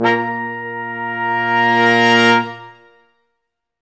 Index of /90_sSampleCDs/Roland LCDP06 Brass Sections/BRS_Quintet sfz/BRS_Quintet sfz